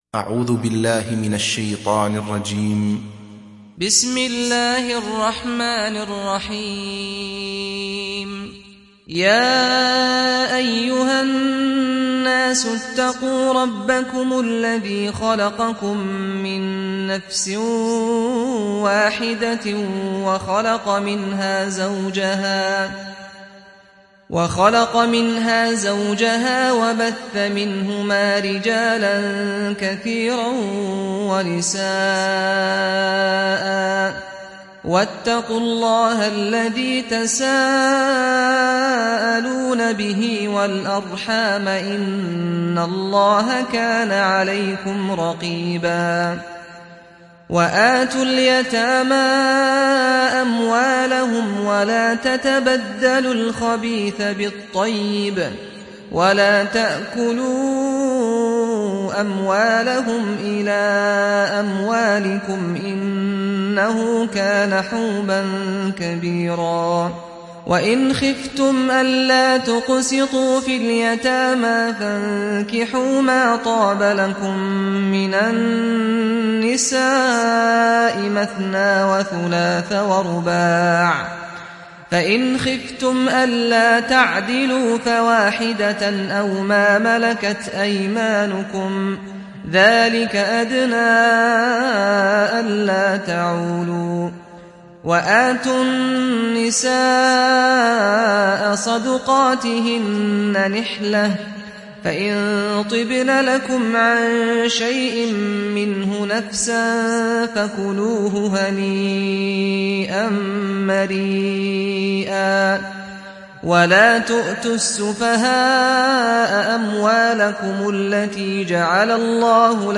Sourate Annisa Télécharger mp3 Saad Al-Ghamdi Riwayat Hafs an Assim, Téléchargez le Coran et écoutez les liens directs complets mp3